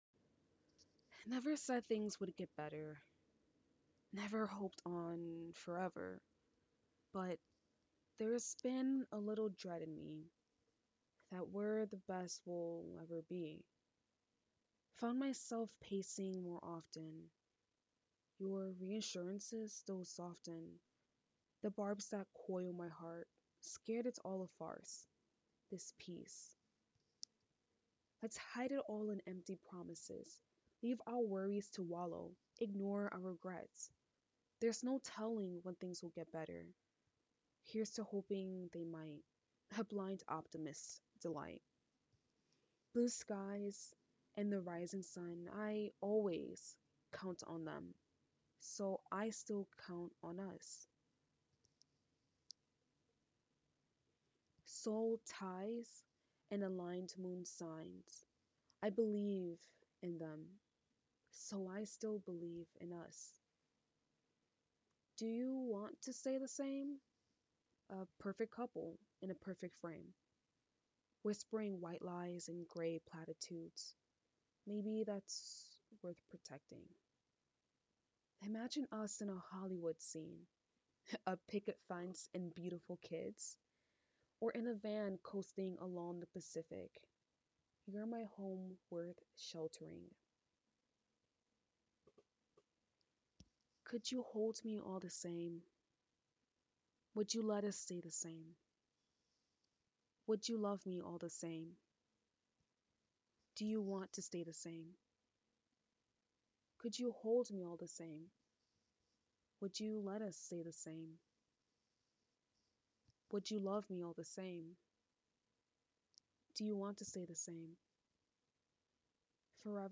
spoken word (demo)